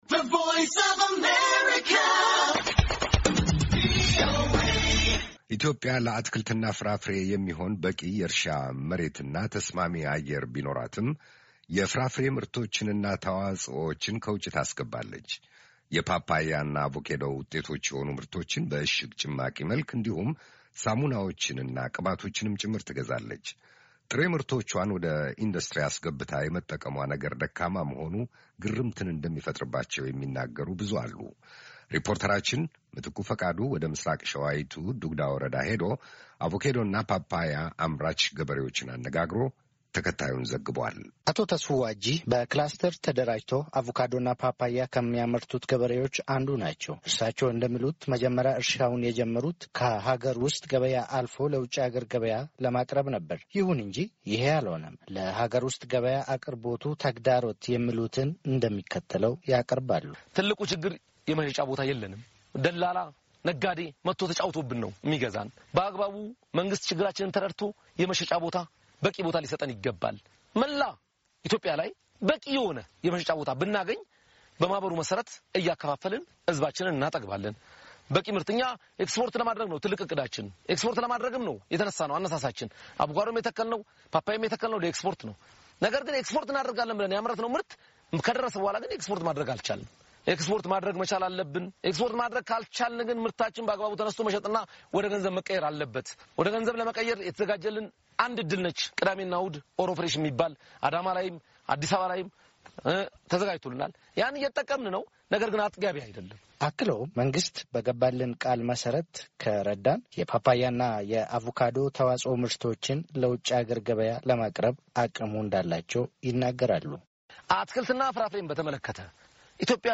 ዘጋቢያችን ወደ ምሥራቅ ሸዋዪቱ ዱግዳ ወረዳ ሄዶ አቮካዶና ፓፓያ አምራች ገበሬዎችን አነጋግሯል።